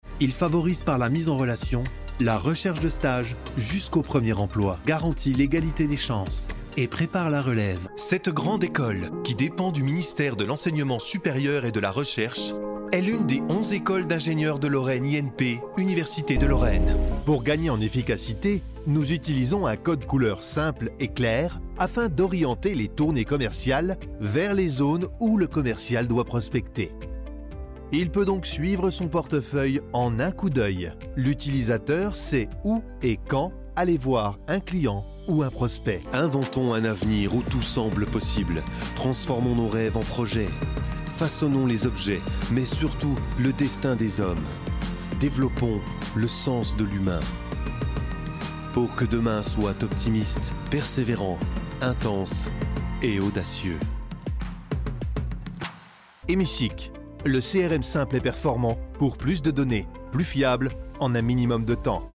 Corporate Videos
Documentaries
IVR
I am a professional french voicer over from 5 years with a smooth young voice, with some pretty bass; i can easily add modulations on my voice.
Isolated Cabin